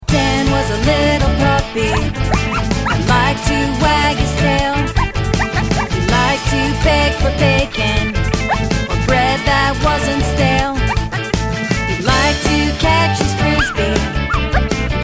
Children's Animal Song Lyrics and Sound Clip